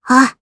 Gremory-Vox_Attack5_jp.wav